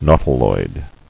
[nau·ti·loid]